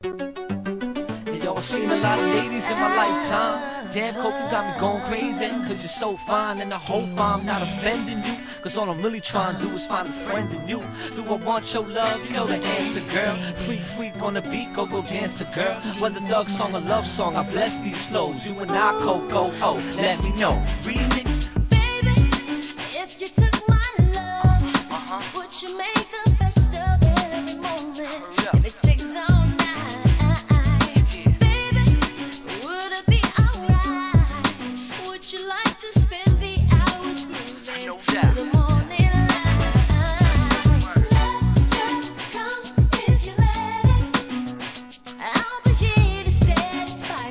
HIP HOP/R&B
キャッチー且つPOPなR&Bナンバーで